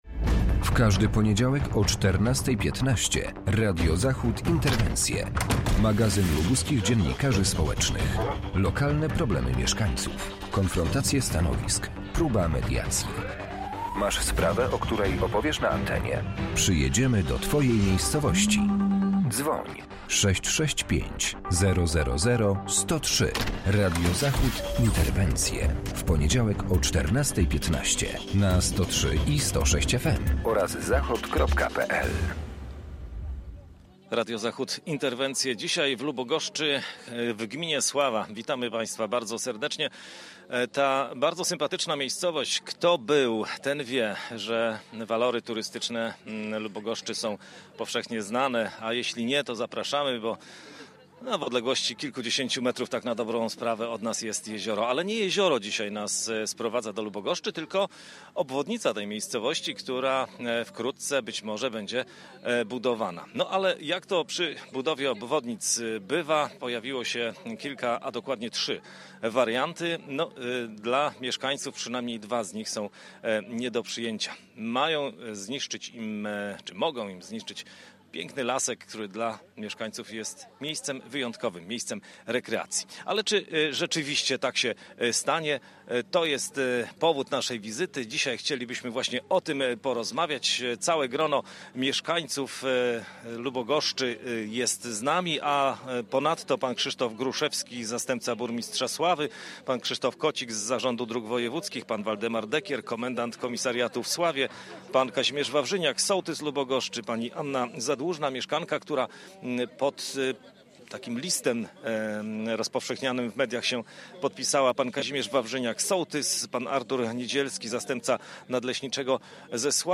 Odpowiedzi we wspólnej dyskusji będą szukać mieszkańcy, samorządowcy, leśnicy, policjanci i drogowcy.